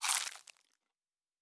pgs/Assets/Audio/Fantasy Interface Sounds/Food Eat 02.wav at master
Food Eat 02.wav